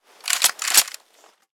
Wpn_rifleassaultg3_jam.ogg